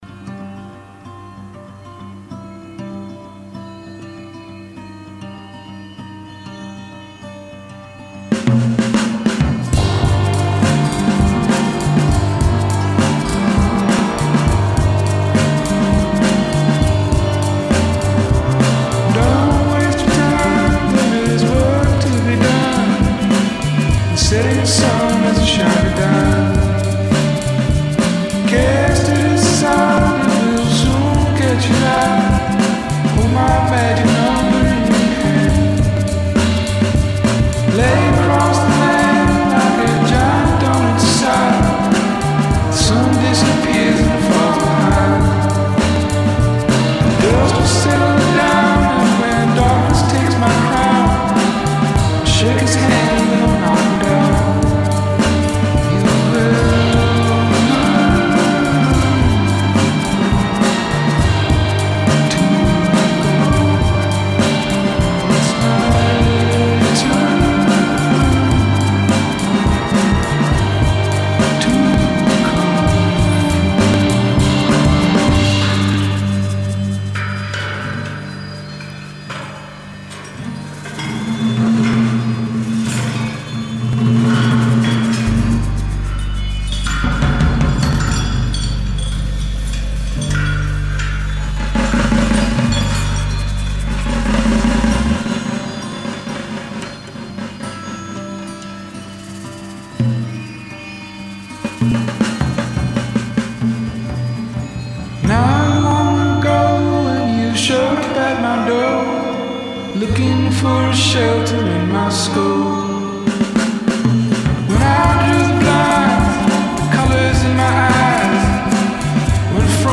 Gure aldizkari sonoroak askotariko estiloak nahastu ditu saio honetan.